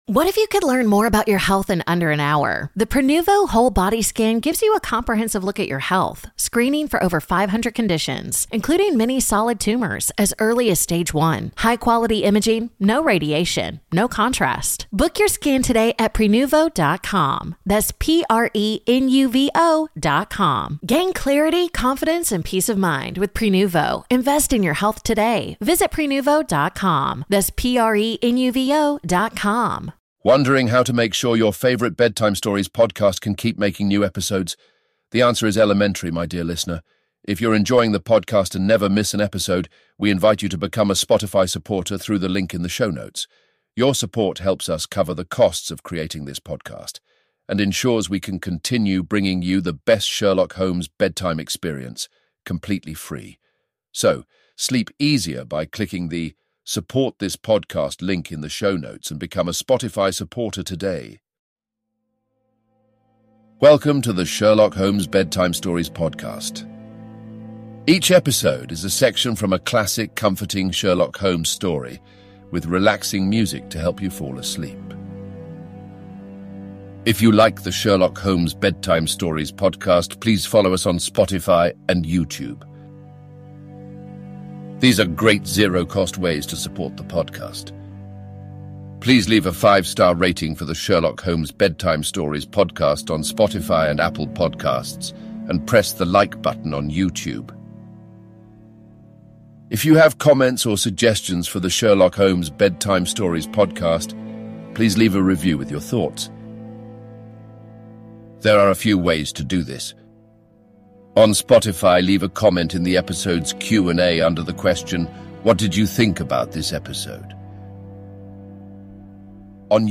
Each episode is a section of a classic Sherlock Holmes story, read in soothing tones and set to calming music to help you fall asleep.